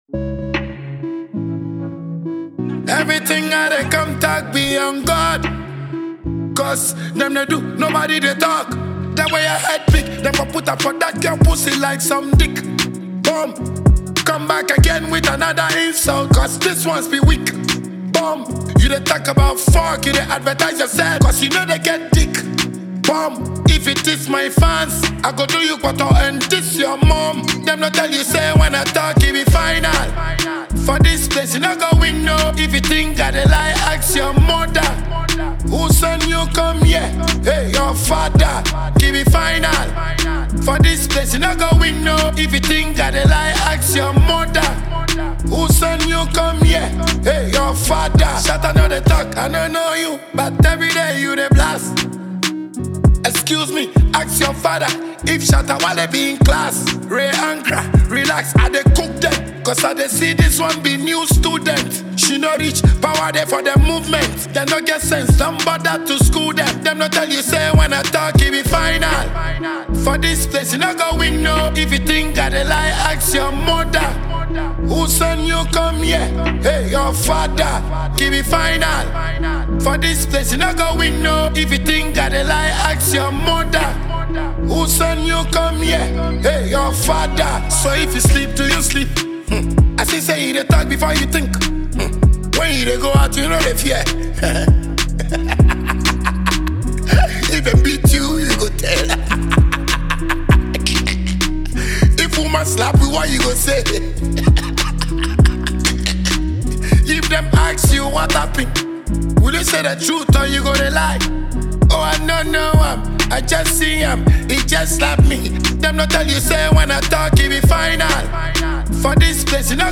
dancehall
bold, energetic release
energetic, memorable, and full of personality